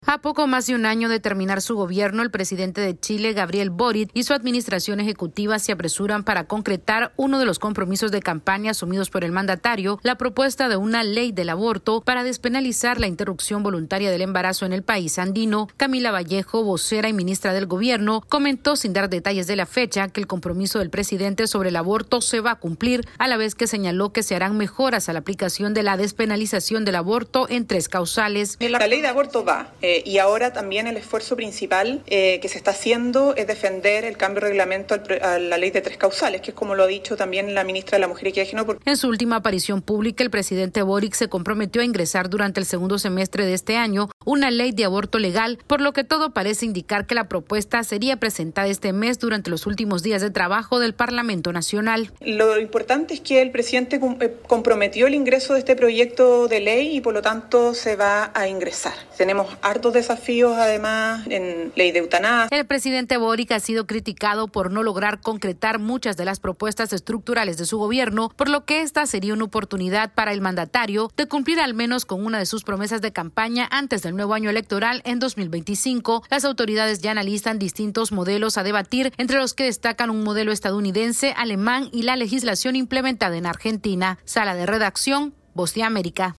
La Administración del presidente Gabriel Boric en Chile trabaja contrarreloj para concretar el último gran compromiso del mandatario, la despenalización del aborto voluntario. Esta es una actualización de nuestra Sala de Redacción.